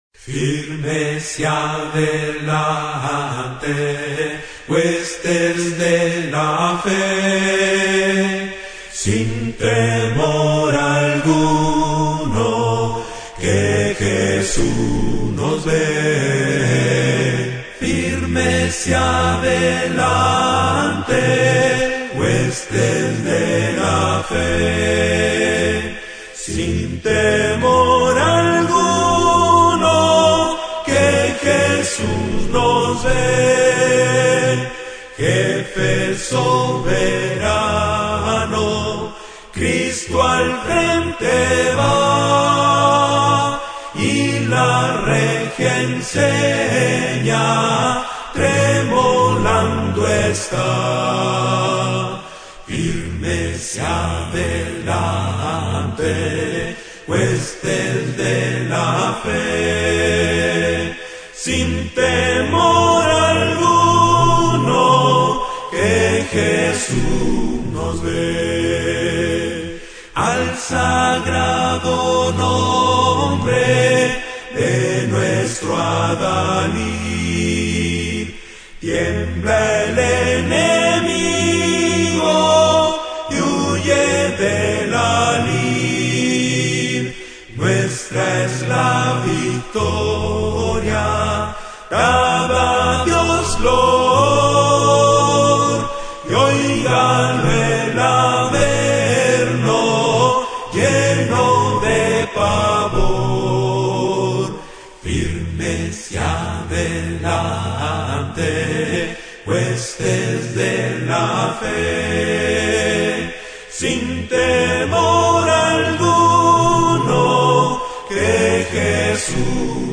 Himnos Acapella